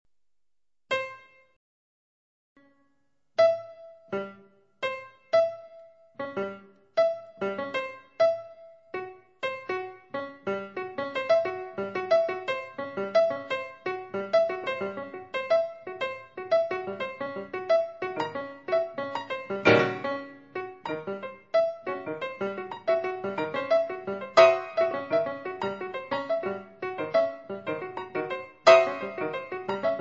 Per pianoforte a quattro mani